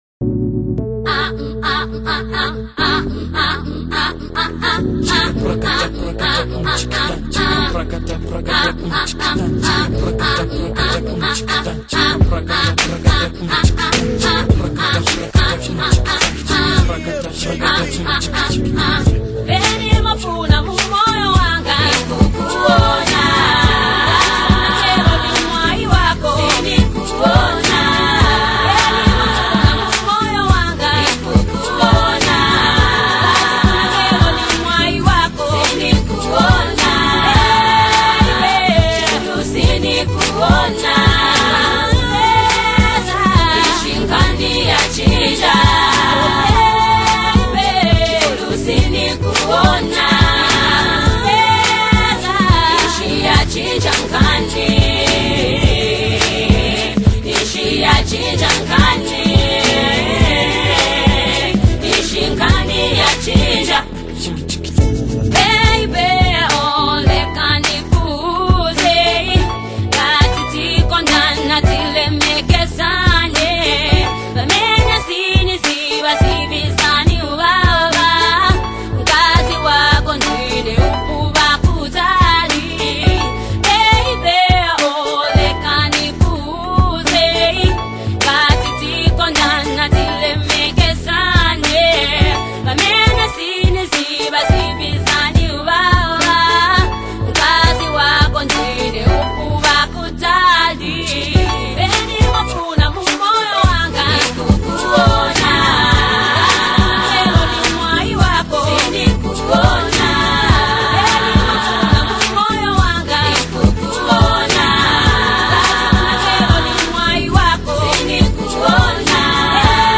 Zambian songstress